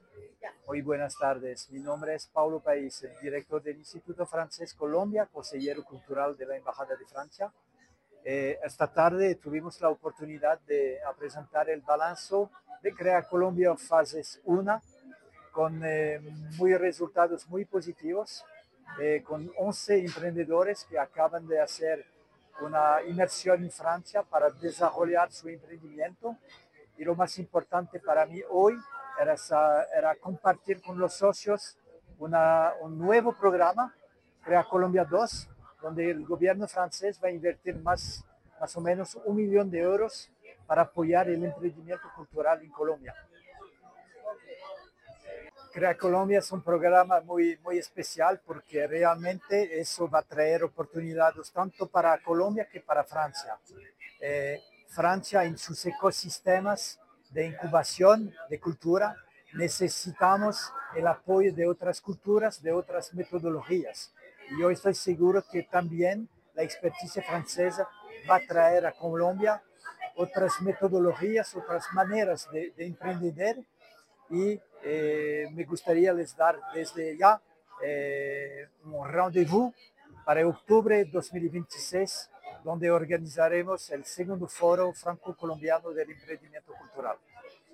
En entrevista con Caracol Radio